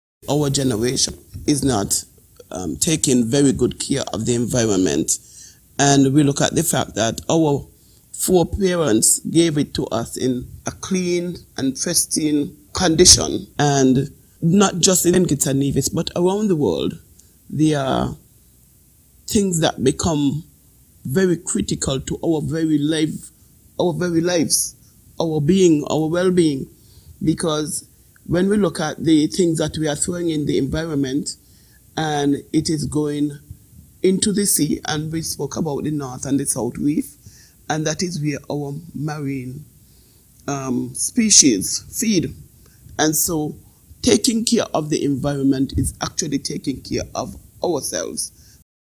During a recent interview